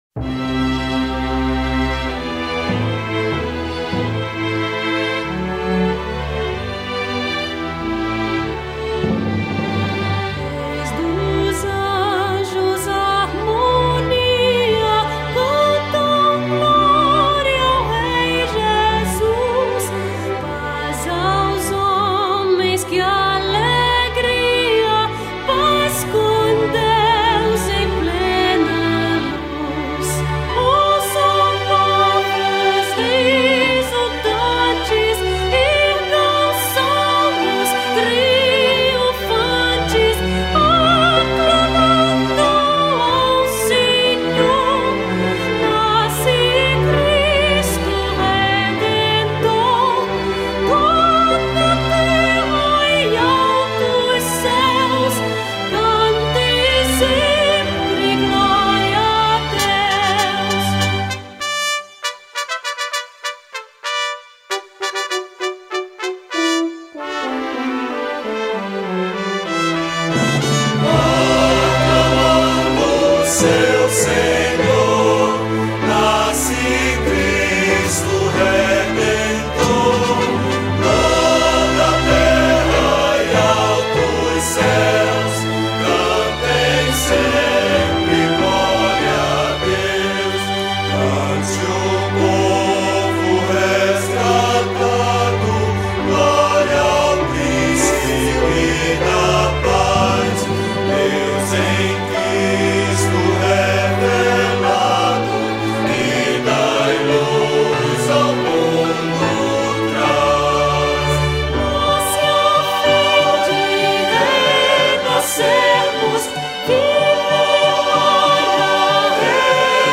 1046   02:35:00   Faixa:     Canção Religiosa